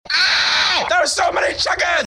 funne craftee reaction Meme Sound Effect
Category: Reactions Soundboard